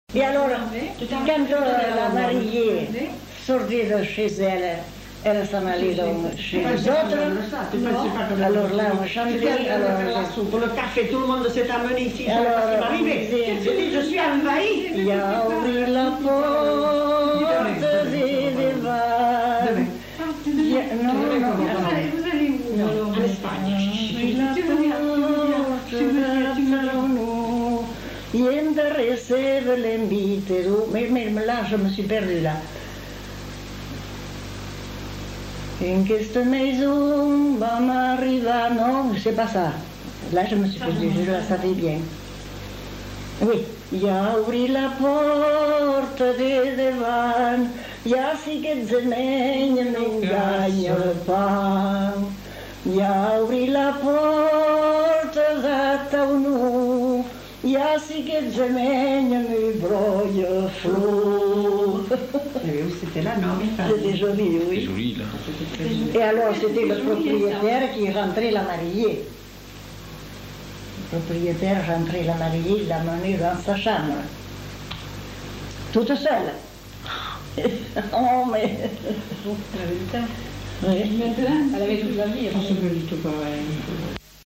Aire culturelle : Marsan ; Petites-Landes
Genre : chant
Effectif : 1
Type de voix : voix de femme
Production du son : chanté